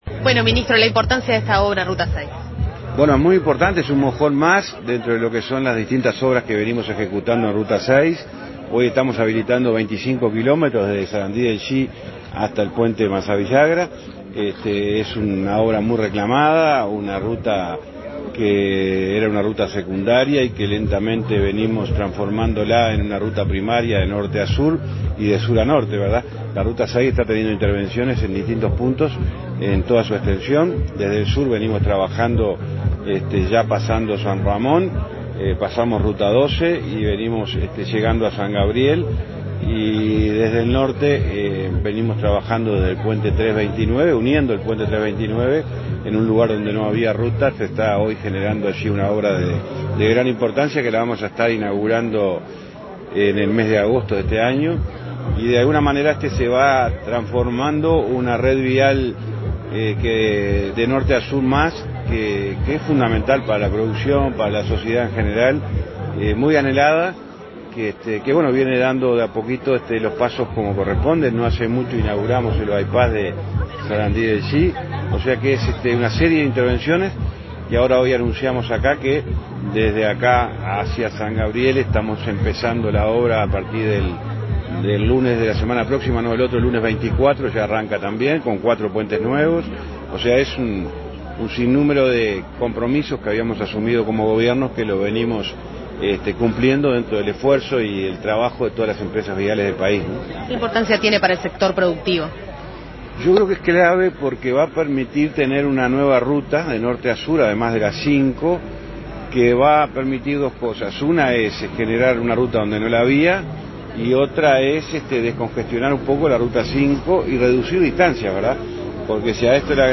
Entrevista al ministro del MTOP, José Luis Falero
Entrevista al ministro del MTOP, José Luis Falero 10/06/2024 Compartir Facebook X Copiar enlace WhatsApp LinkedIn Tras participar en la inauguración de obras en el tramo de ruta 6 en Capilla del Sauce, Florida, este 10 de junio, el titular del Ministerio de Transporte y Obras Públicas (MTOP), José Luis Falero, realizó declaraciones a Comunicación Presidencial.